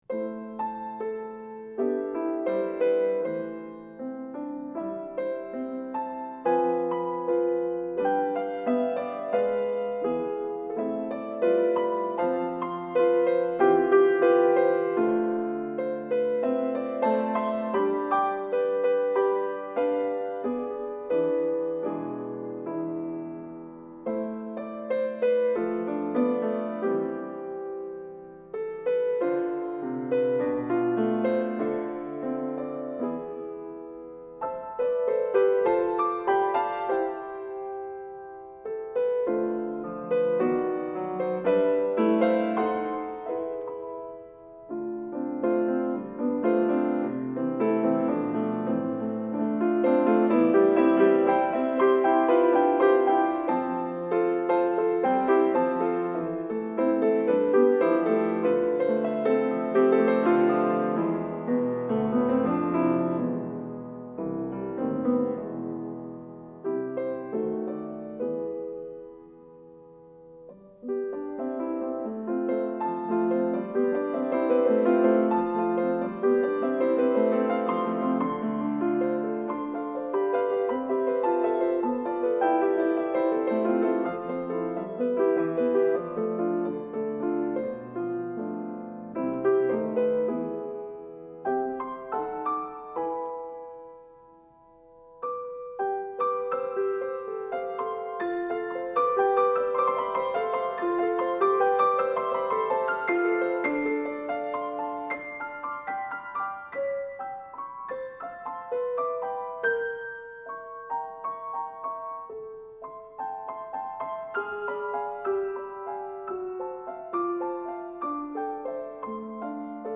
1999 Prelude no 2 Piece for piano -